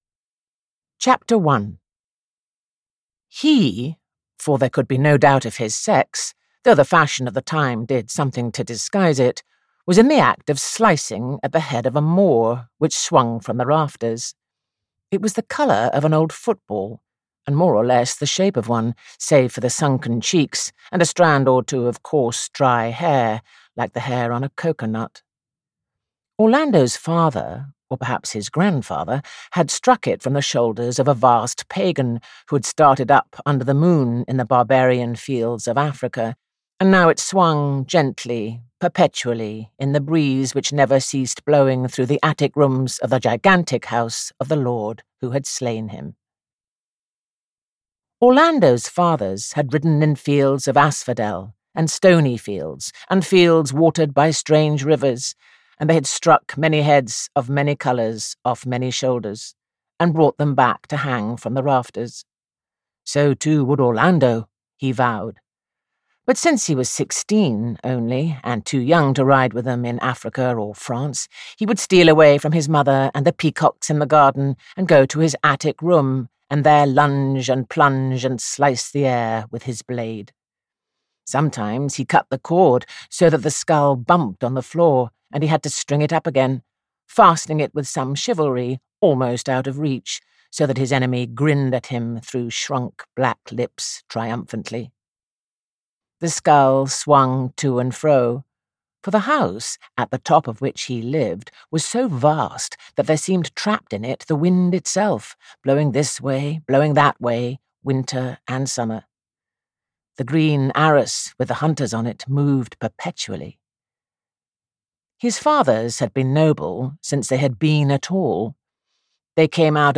Orlando audiokniha
Ukázka z knihy
• InterpretJuliet Stevenson